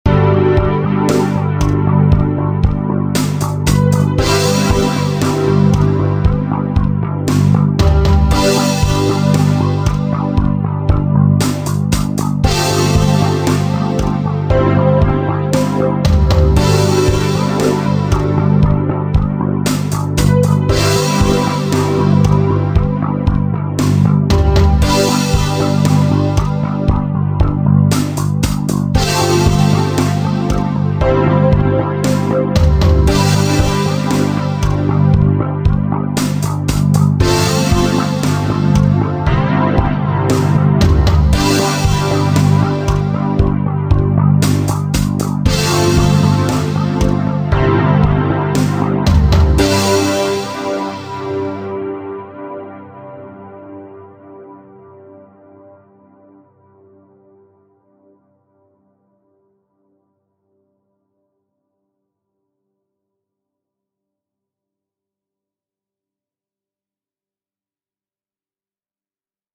Romance – Free Stock Music